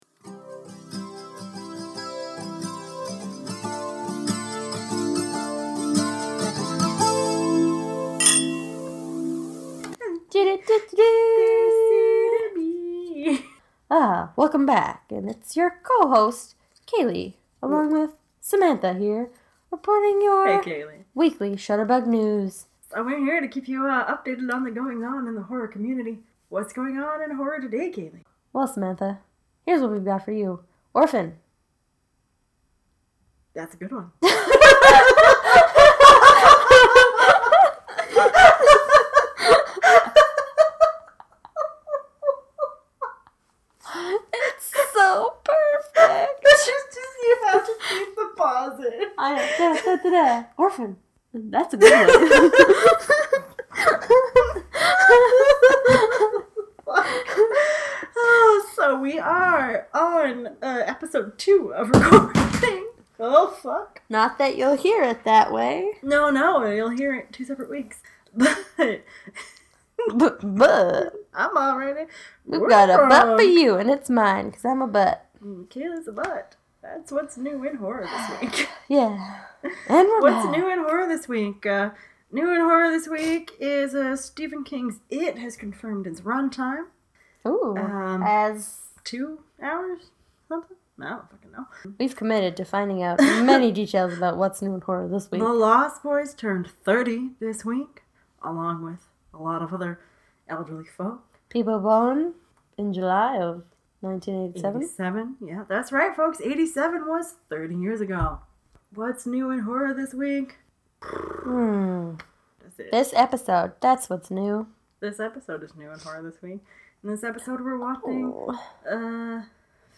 We get vodka drunk and watch 2009’s most impressive horror, “Orphan.” We get rowdy, we laugh so hard we snort, and we actually get a little scared.